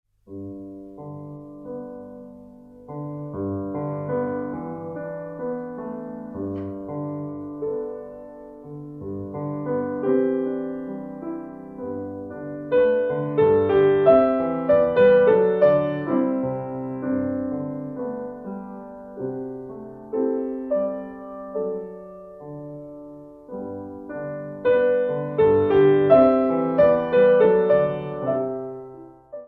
for solo piano